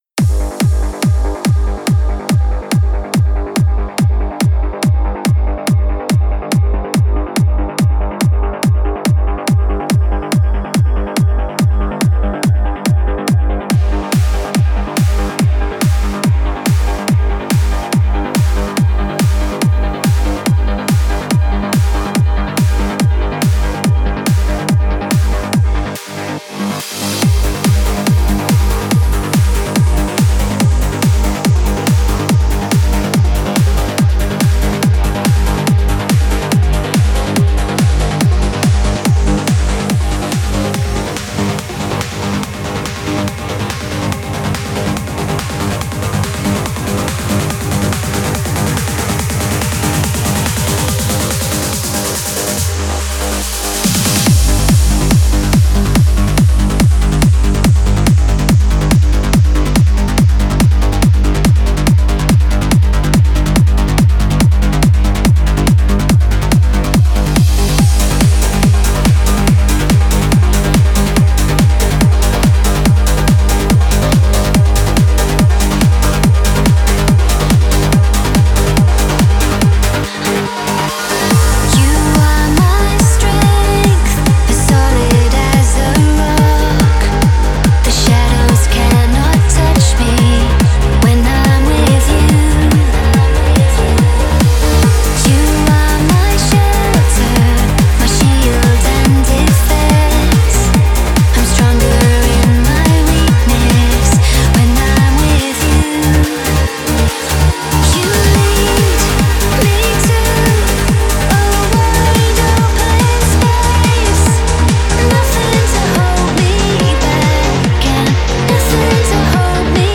Стиль: Trance / Vocal Trance / Uplifting Trance